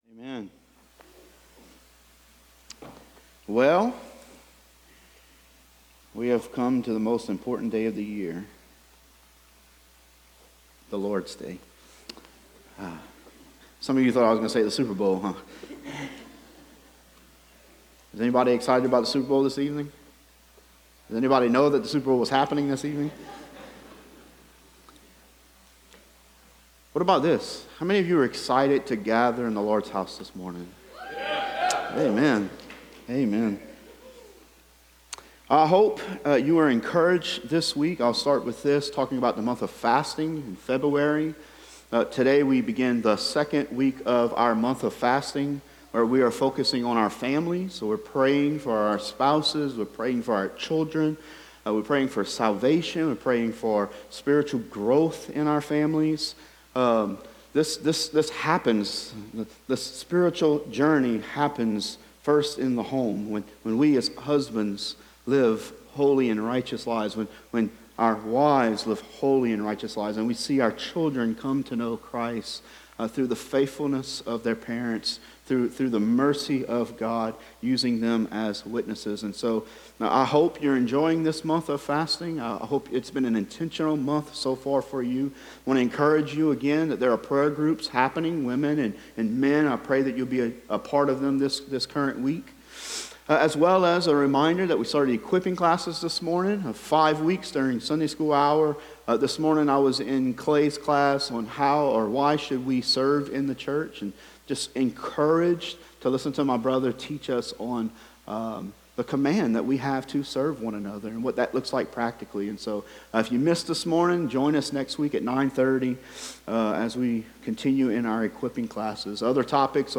Sermons – FBC Thibodaux